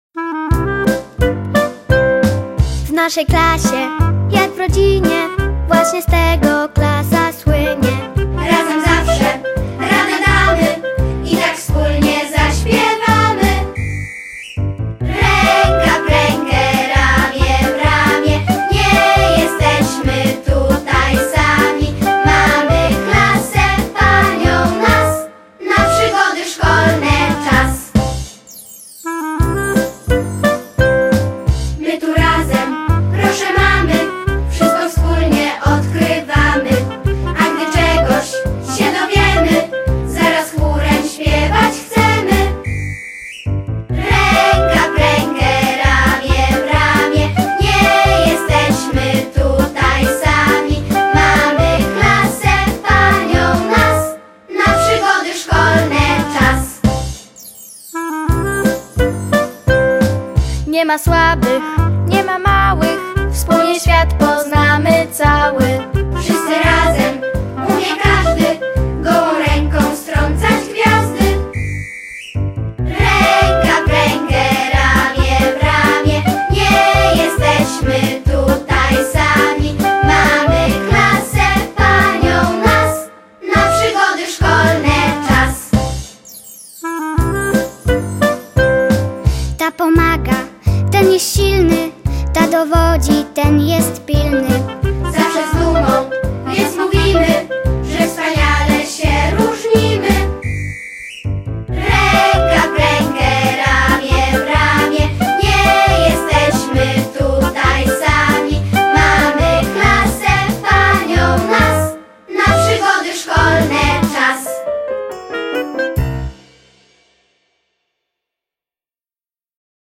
W naszej klasie jak w rodzinie (wersja wokalno-instrumentalna)